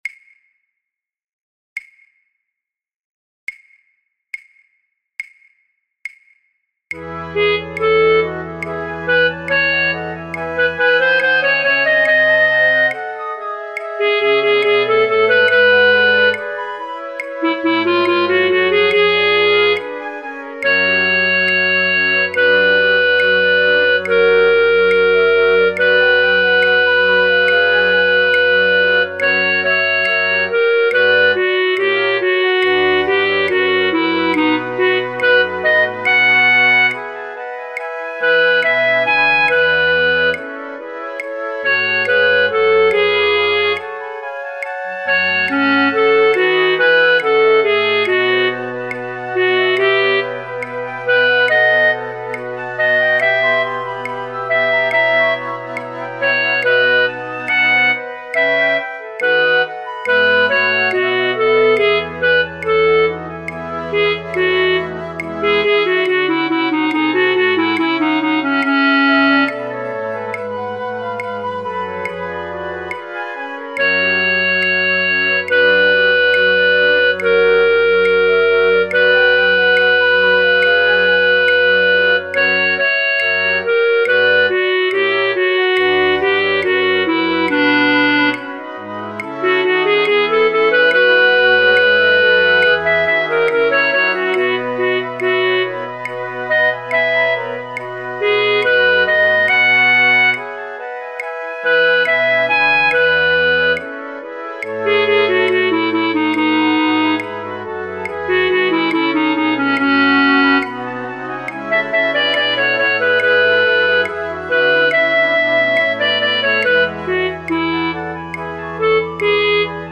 Matthäus Passion – Oefentracks | Flutopia
alle partijen